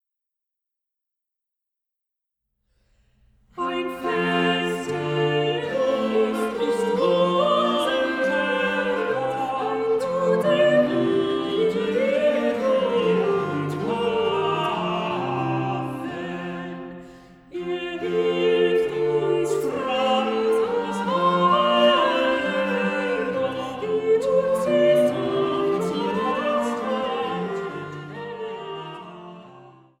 Sopran
Tenor
Blockflöte
Viola da gamba
Orgel, Virginal